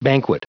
Prononciation du mot banquet en anglais (fichier audio)
Prononciation du mot : banquet